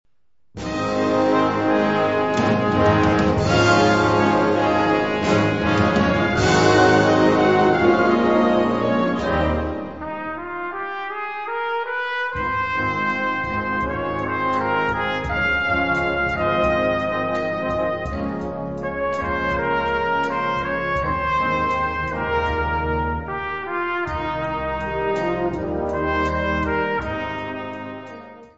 Gattung: Solo für Trompete
Besetzung: Blasorchester